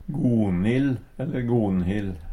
Alle namn som endar på "-hild" endar på noringsmål med "-ill".